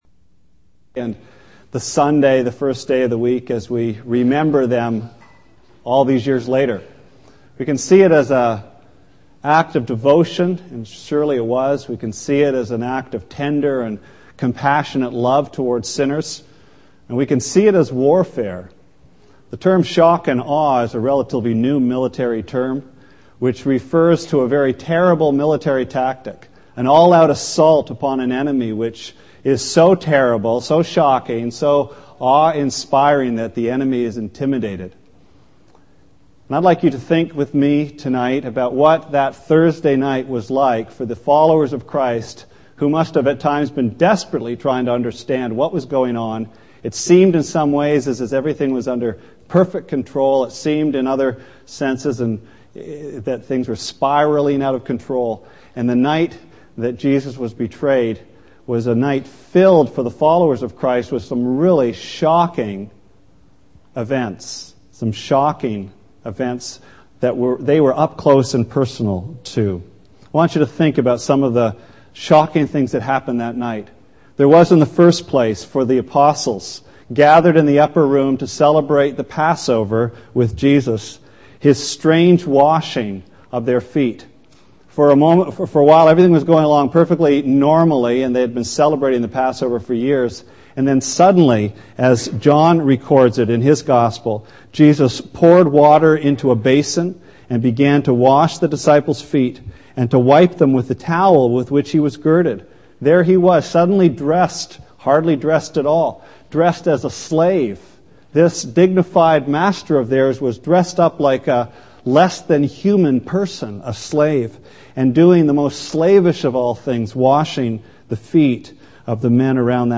Sermon Archives
Maundy Thursday service